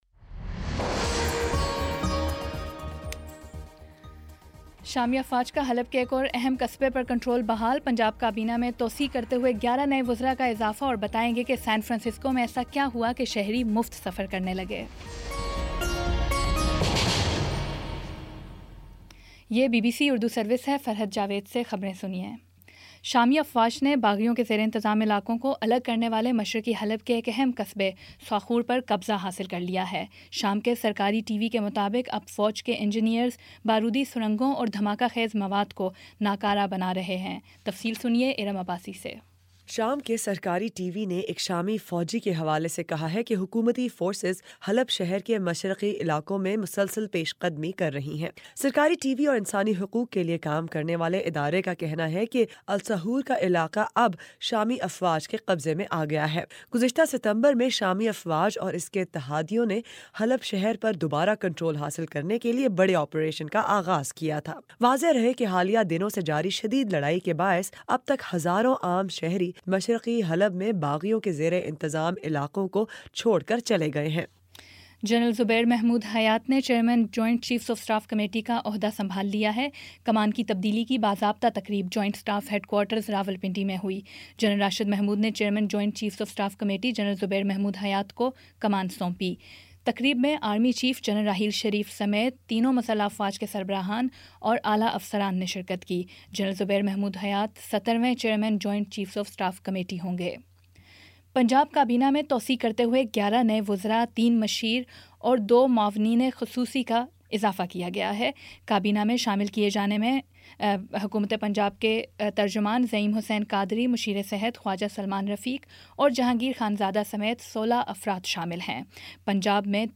نومبر 28 : شام پانچ بجے کا نیوز بُلیٹن